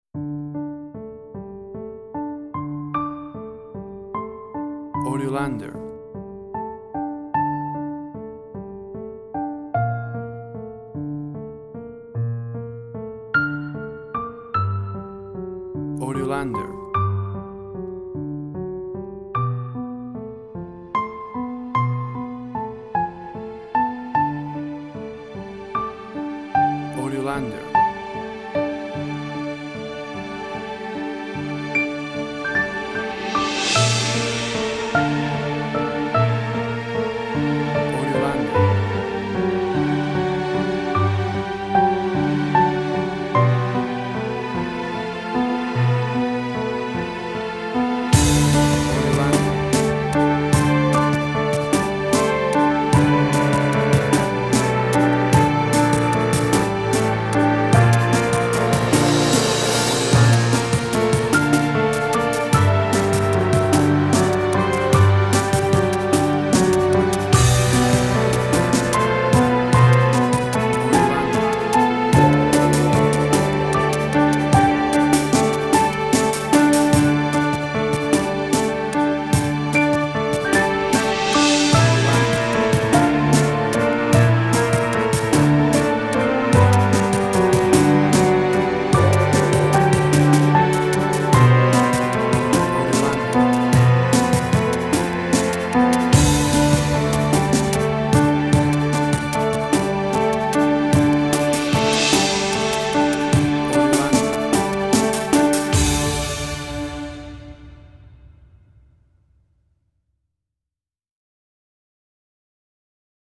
Indie Quirky
Tempo (BPM): 75